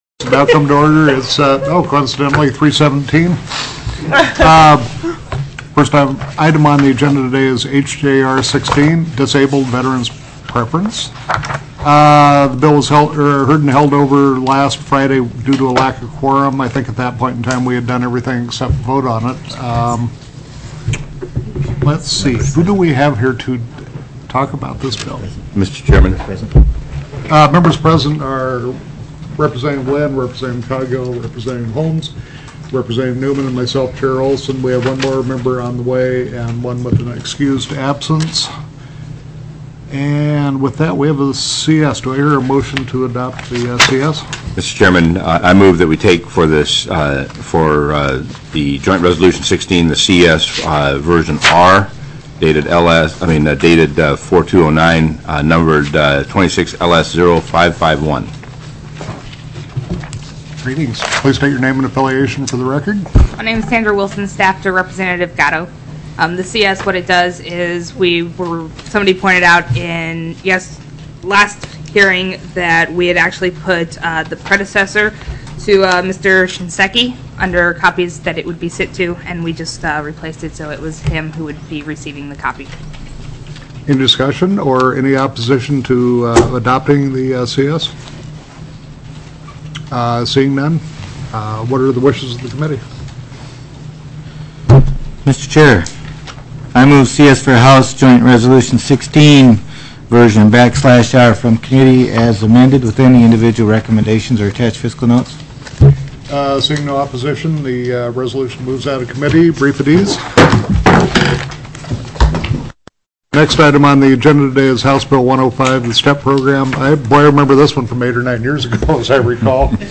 += HJR 16 DISABLED VETERANS PROCUREMENT PREFERENCE TELECONFERENCED
+= HB 105 STEP PROGRAM TELECONFERENCED
The committee took an at-ease from 3:20 p.m. to 3:21 p.m.